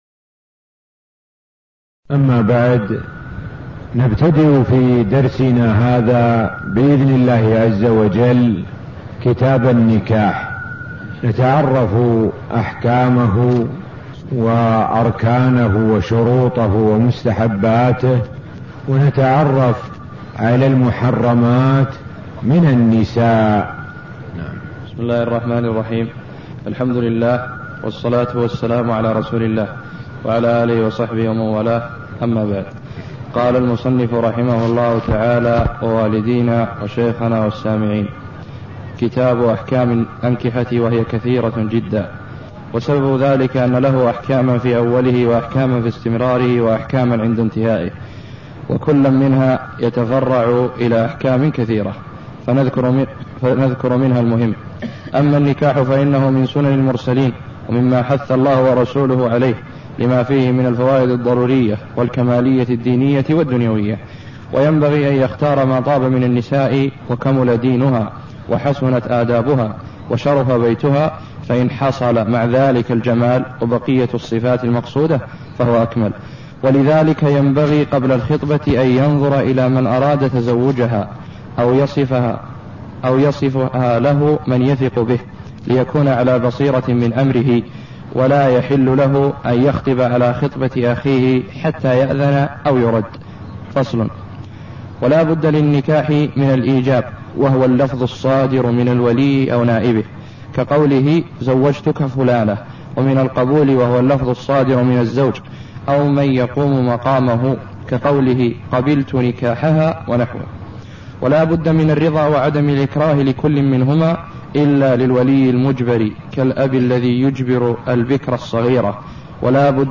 تاريخ النشر ١٨ محرم ١٤٣١ هـ المكان: المسجد النبوي الشيخ: معالي الشيخ د. سعد بن ناصر الشثري معالي الشيخ د. سعد بن ناصر الشثري كتاب النكاح – باب اركانه وشروطه والمحرمات من السناء (0001)